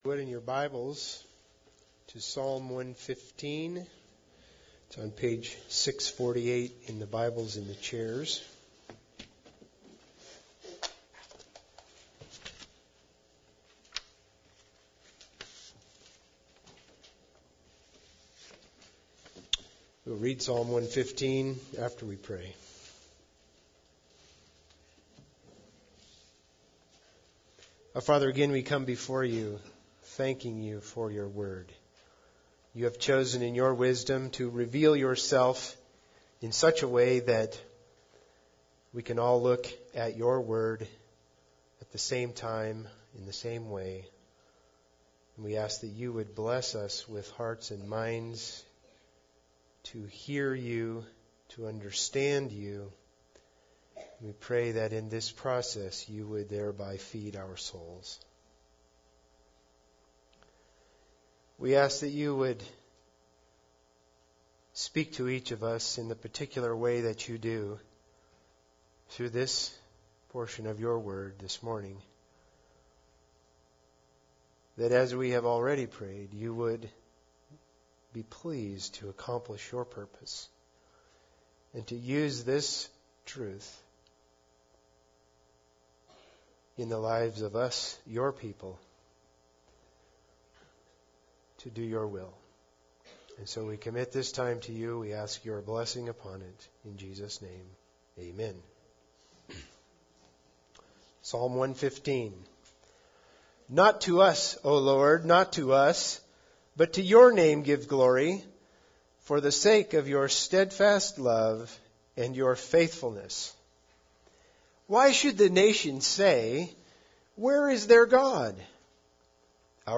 Psalms 115:1-8 Service Type: Sunday Service Bible Text